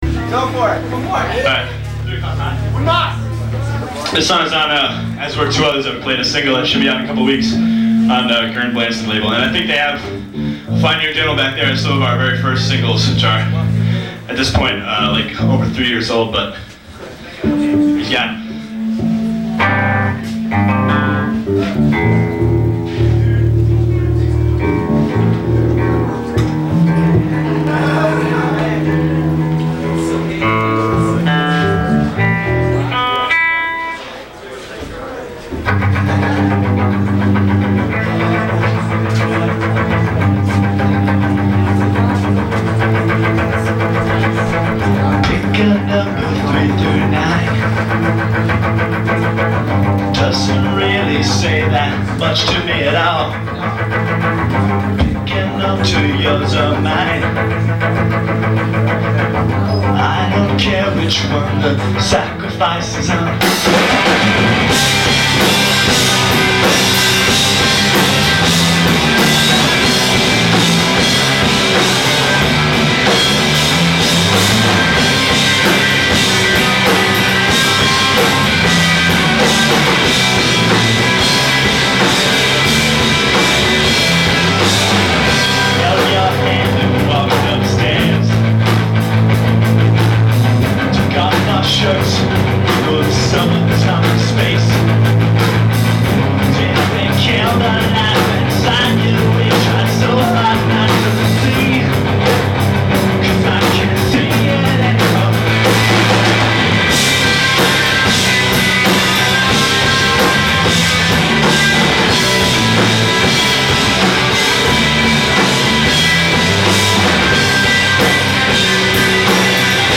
live at Burlingtonitus I
Burlington, Vermont